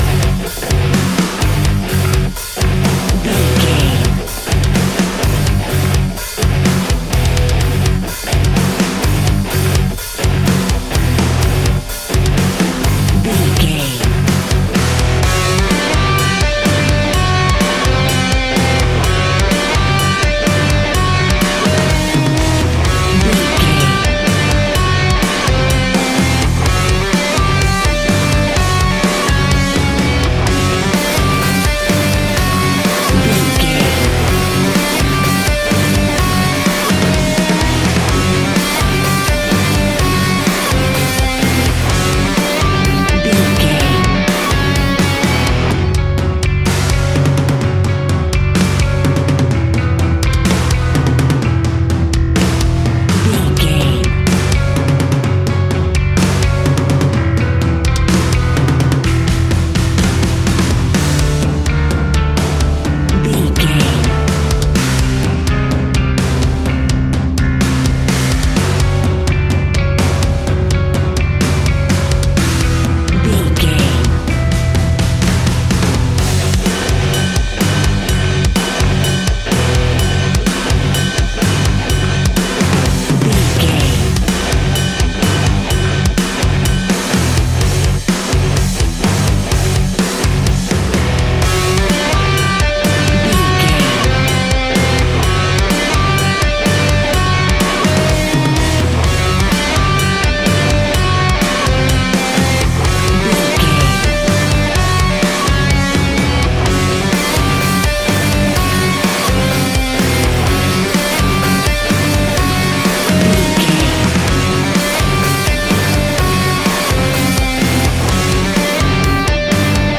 Ionian/Major
F♯
hard rock
heavy metal